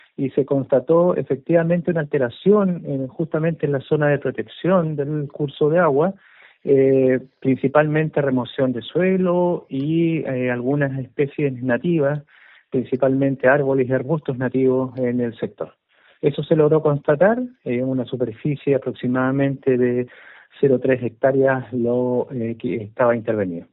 Al respecto, el director regional de Conaf, Arnoldo Shibar, dijo que “se constató efectivamente una alteración en la zona de protección del curso de agua, principalmente remoción de suelo y algunas especies nativas, principalmente árboles y arbustos nativos en el sector”.